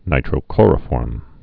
(nītrō-klôrə-fôrm)